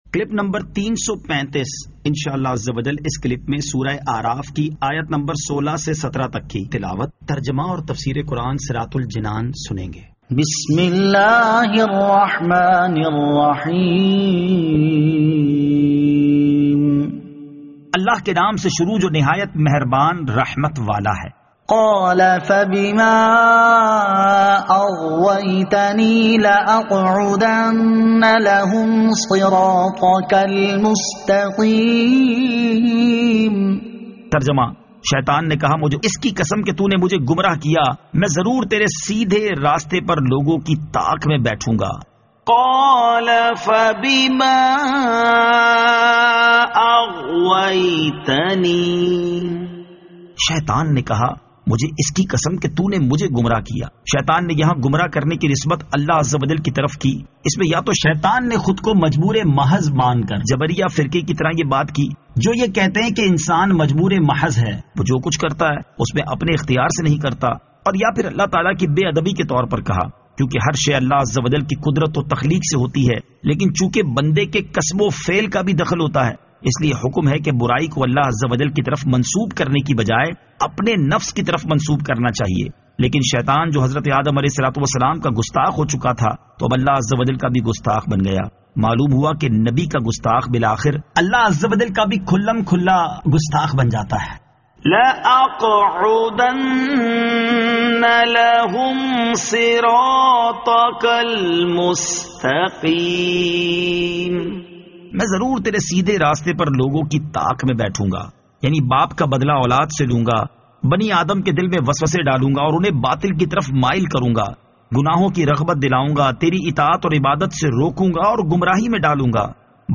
Surah Al-A'raf Ayat 16 To 17 Tilawat , Tarjama , Tafseer